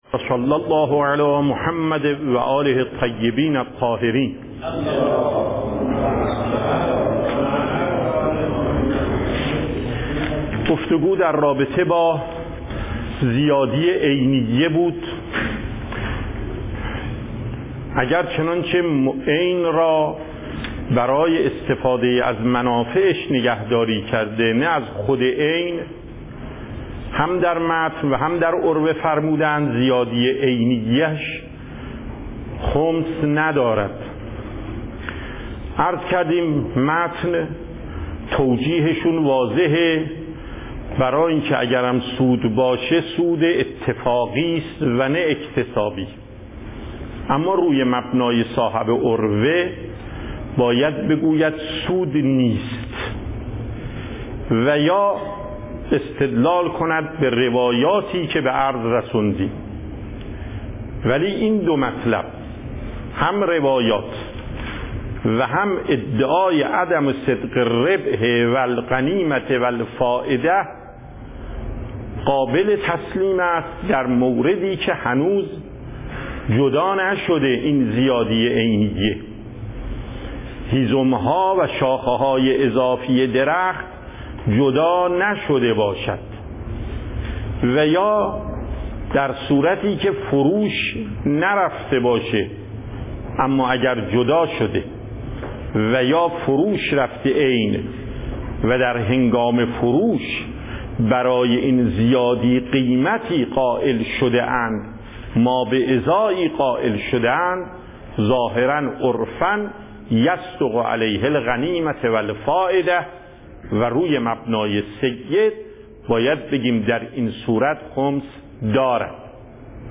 درس فقه آیت الله محقق داماد